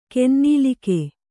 ♪ kennīlike